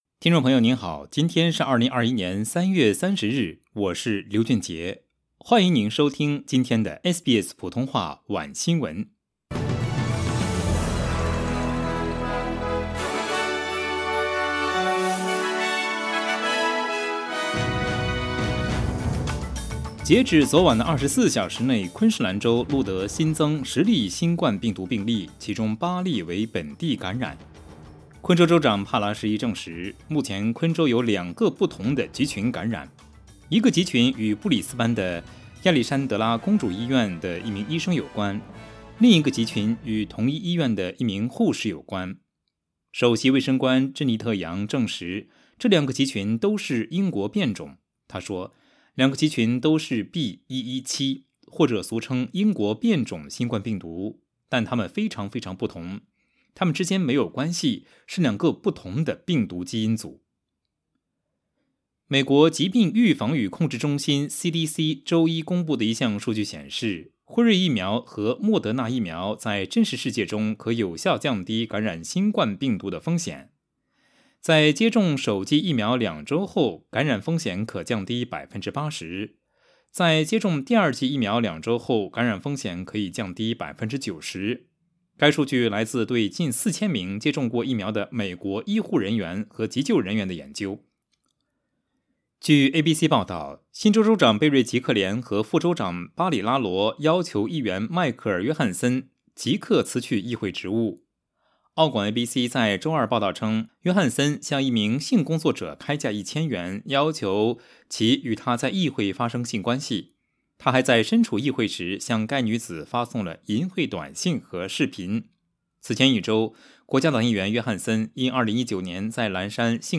SBS晚新闻（3月30日）
SBS Mandarin evening news Source: Getty Images